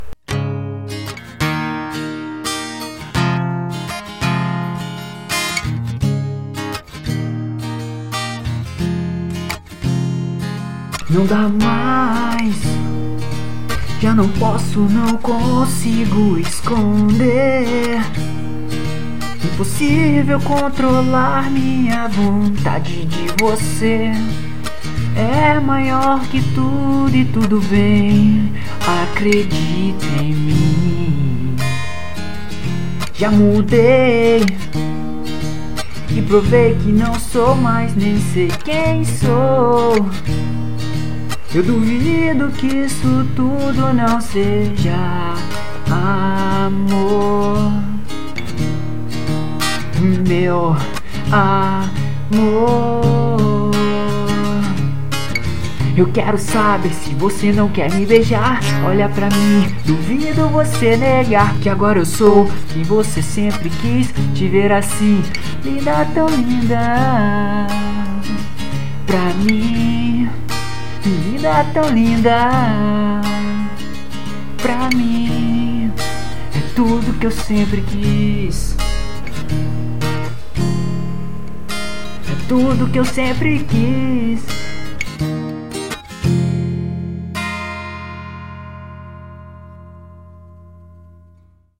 acústico